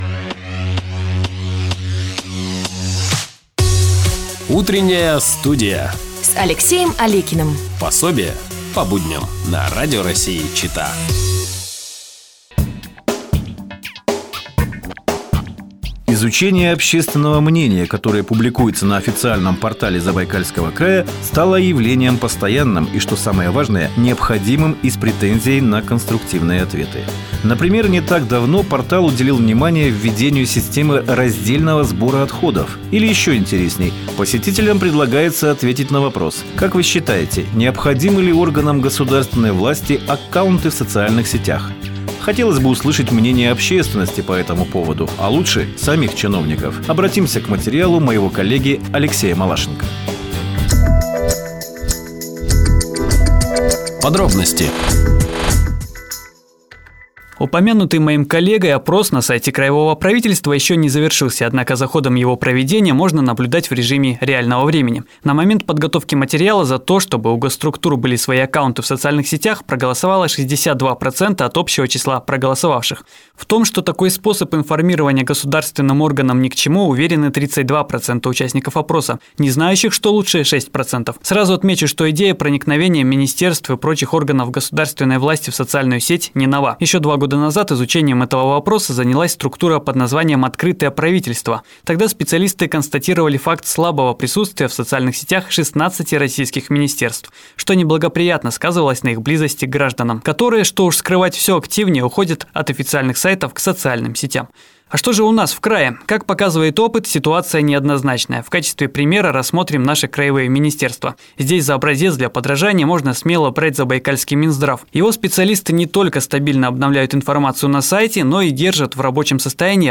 Нужны ли чиновникам аккаунты в социальных сетях? - свое мнение для "Радио России-Чита" высказали депутаты Элина Акулова и Алексей Саклаков